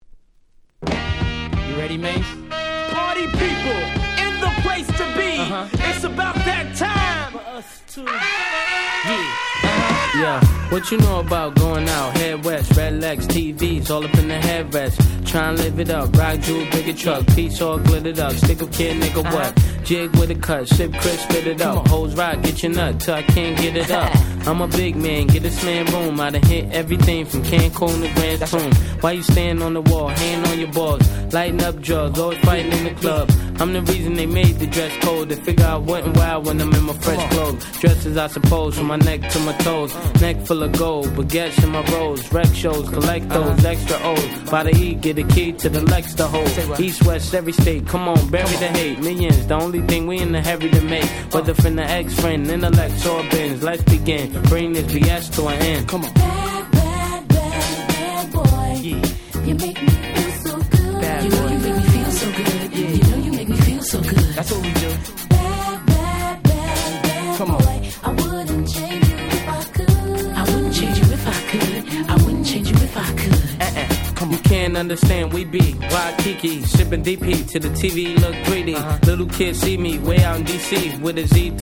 97' Super Hit Hip Hop !!
Boom Bap ブーンバップ